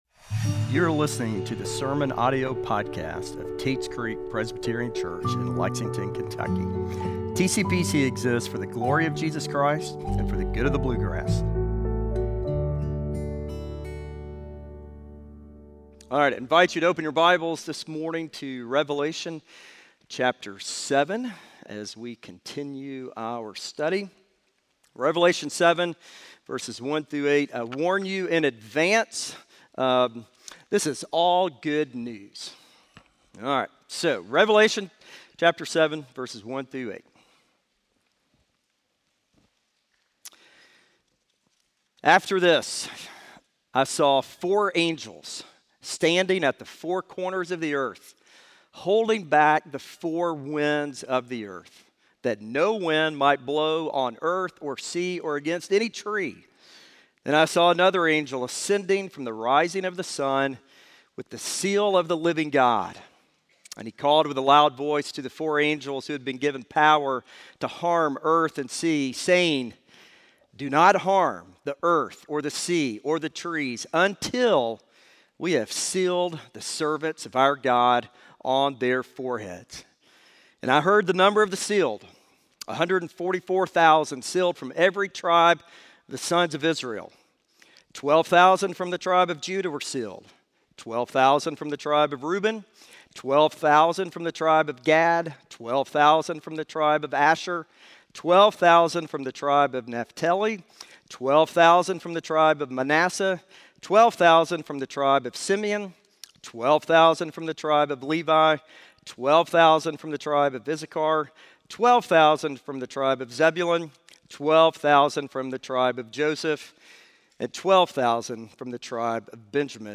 Sealed By God TCPC Sermon Audio podcast